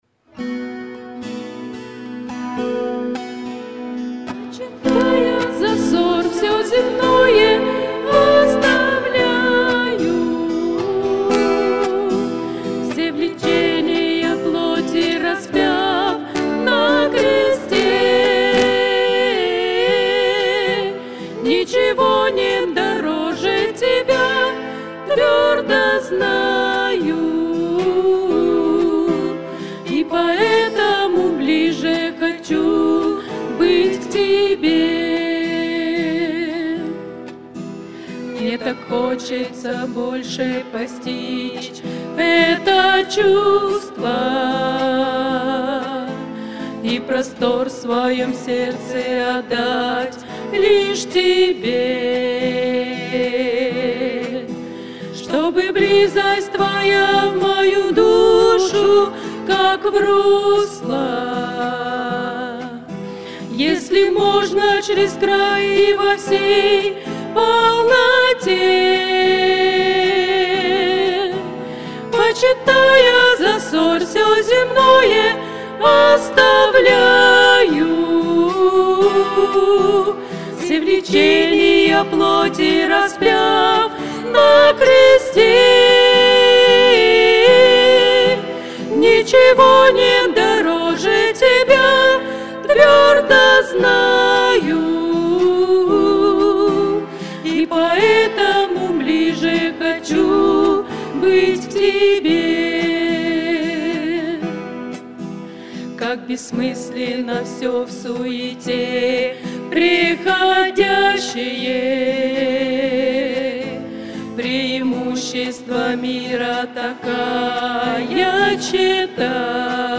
Богослужение 28.09.2024
Пение